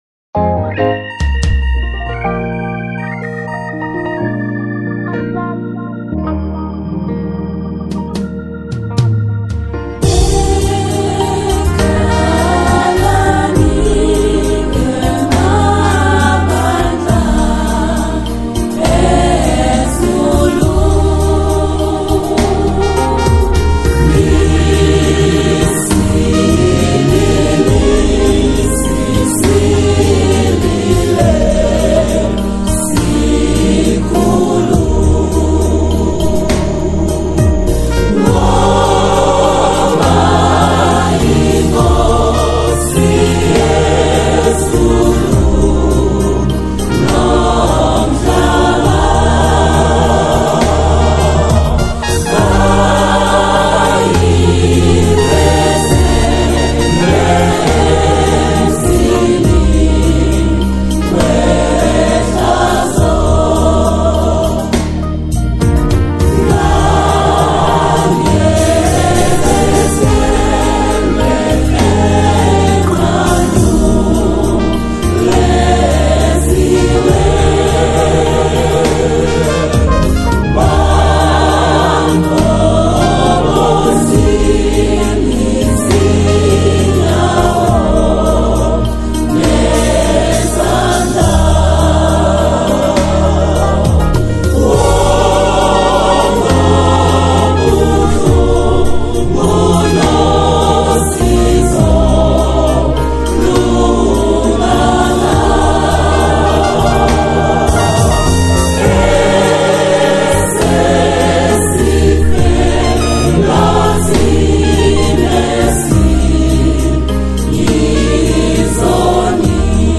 Zulu hymn